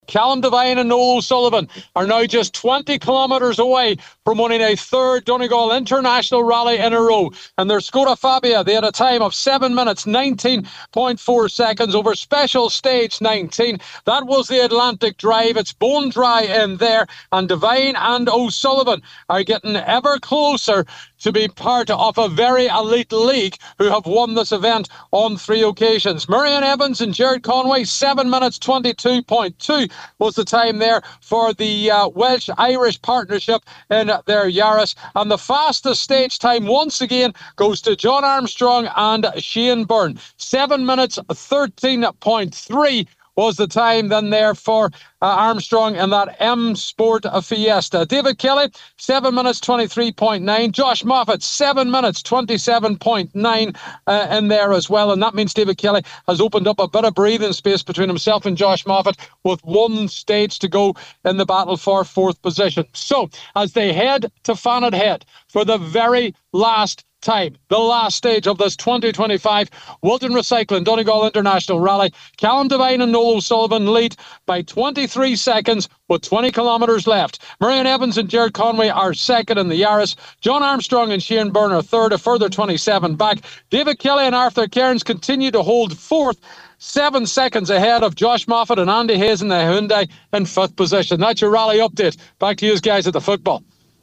With the latest from the penultimate stage at Atlantic Drive